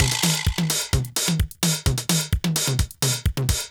CLF Beat - Mix 16.wav